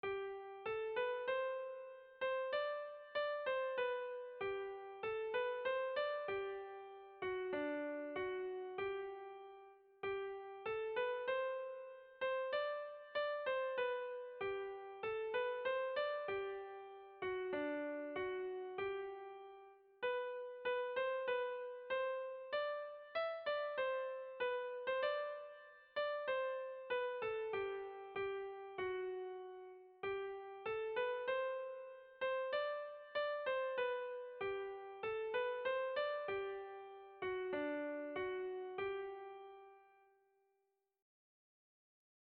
Lau zahar hauek gaituzte egun - Bertso melodies - BDB.
Kontakizunezkoa
Zortziko handia (hg) / Lau puntuko handia (ip)
AABA